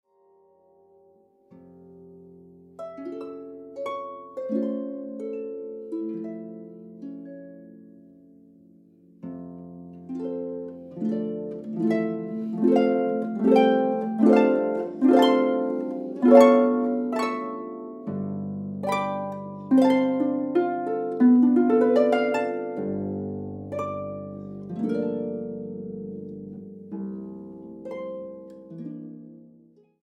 Boonkker Audio Tacubaya, Ciudad de México.